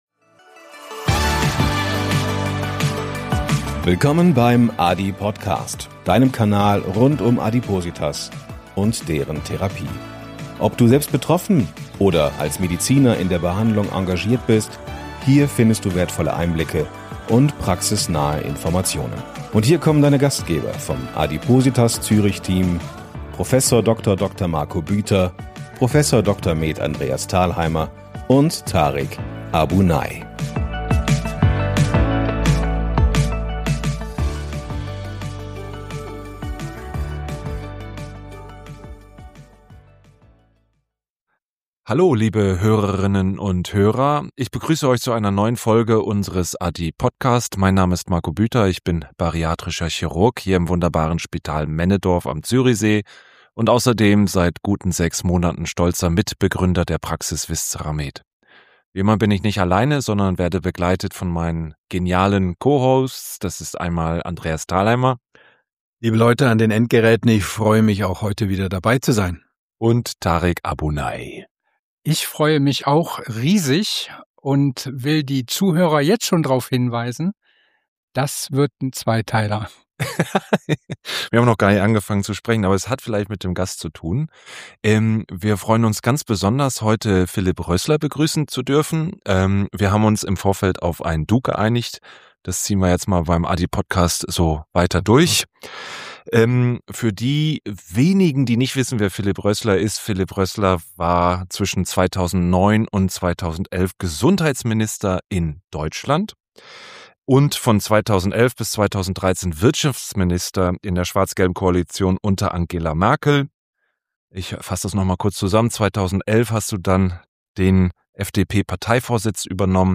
Ein ehrliches Gespräch über unbequeme Wahrheiten, strukturelle Fehlanreize und die Frage, warum Mut in der Gesundheitspolitik so selten ist.